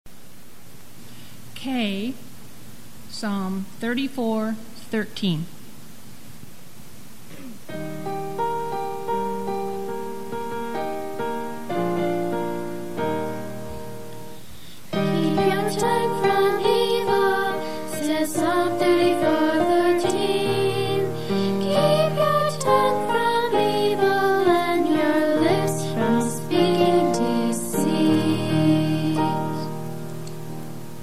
Click the next link to download the audio file of the song for each verse with lyrics, or click the third link in each box for the instrumental music alone.